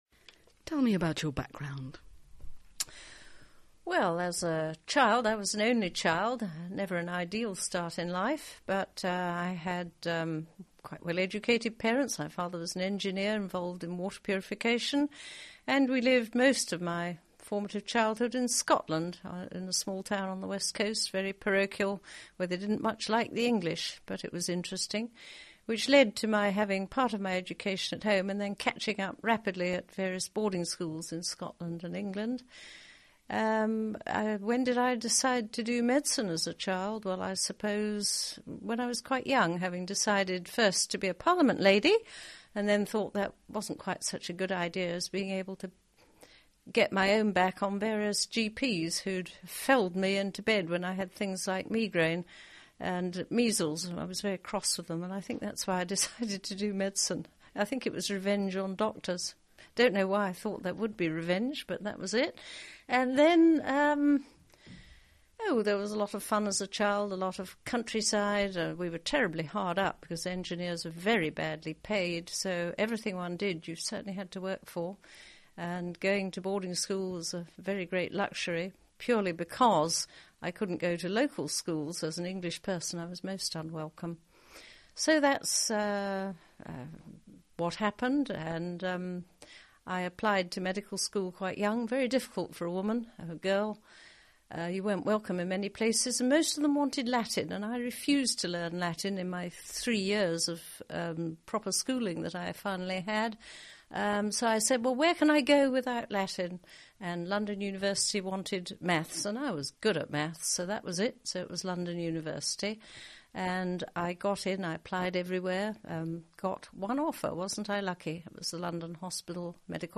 Here you can read through the whole transcript for this interview.